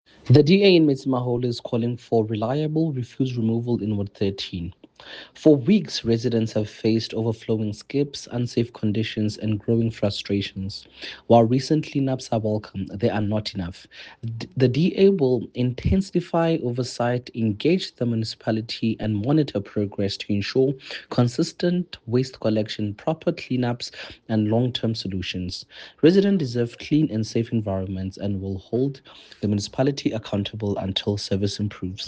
English soundbite by Cllr Teboho Thulo,